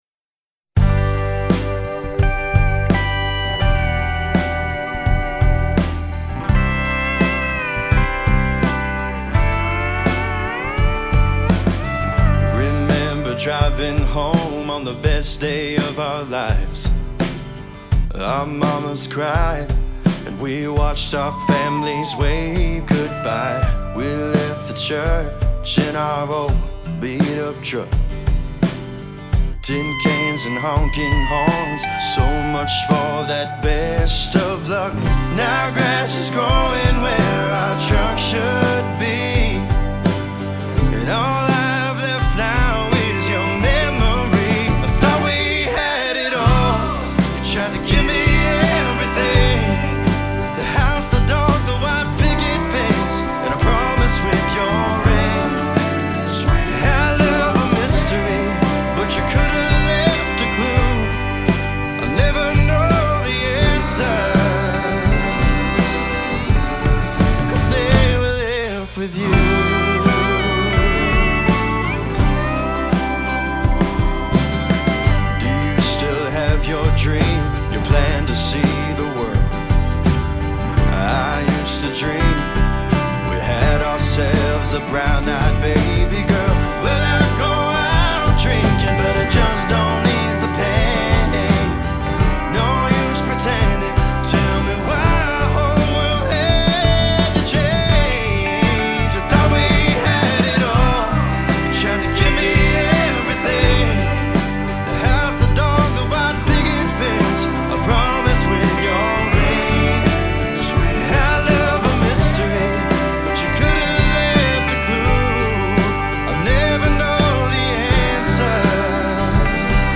A Million Questions • Recorded and mixed at Robbo Music (Morro Bay, CA)
vocals
MSA pedal steel el